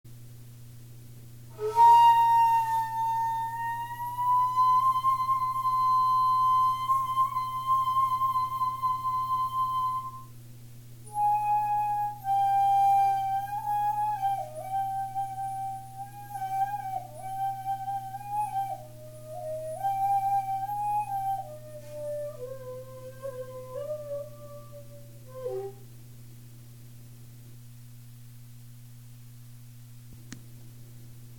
先ず通常の運指で音高を確認しますと図Ｂのようになります。